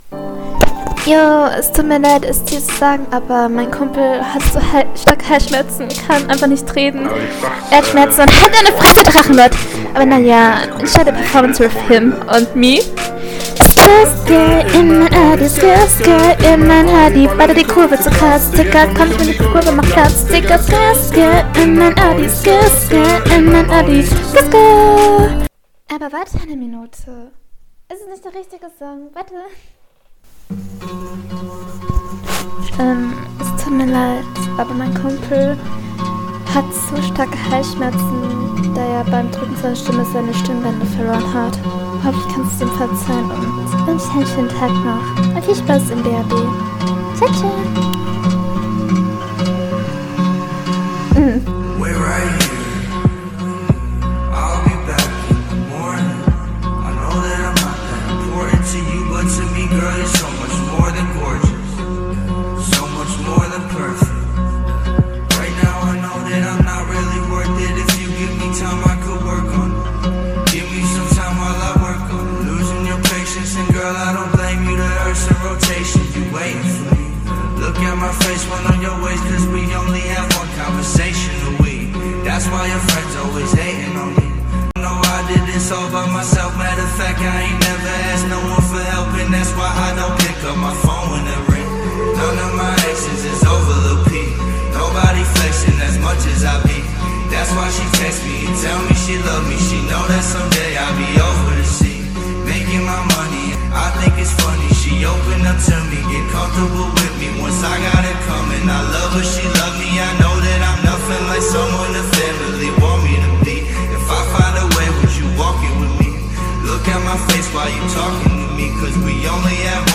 Flow: Text: Soundqualität: Allgemeines: umm ja also anime girl am anfang klang gut ig???????????????????????